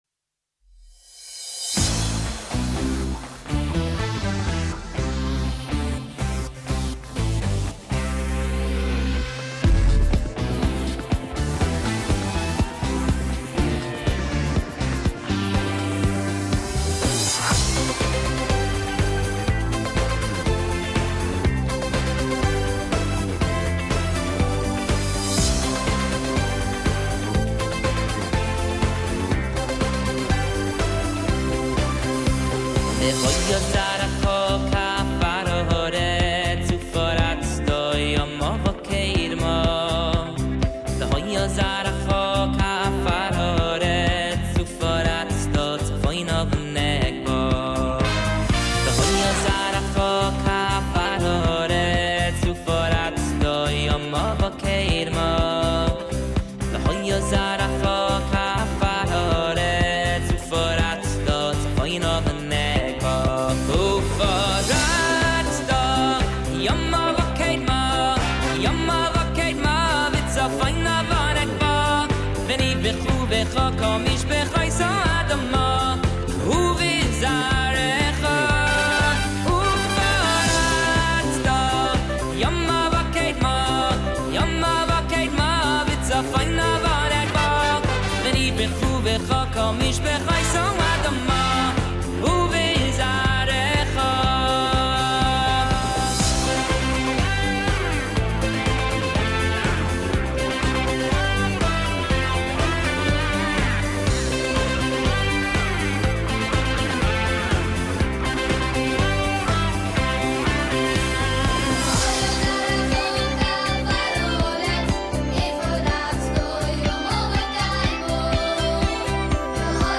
שיר דאנס מז'ורי.